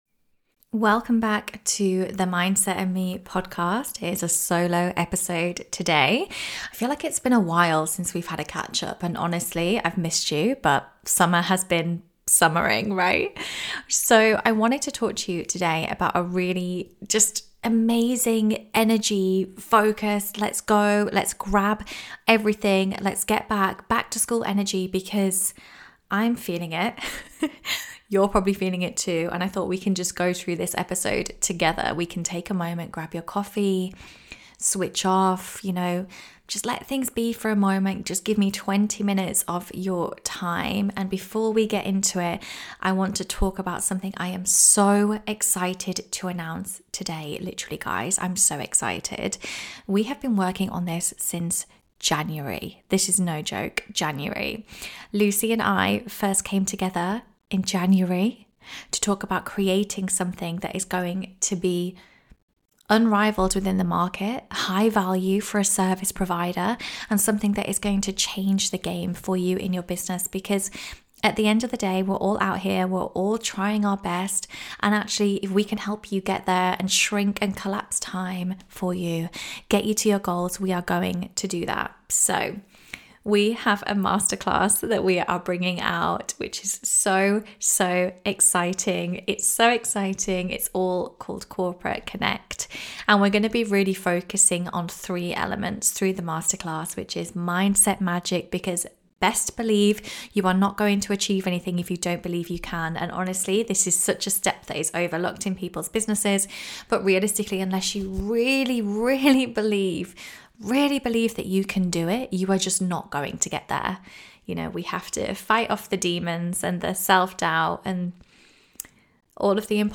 Solo Ep | How to end your year with success and set up Q4 now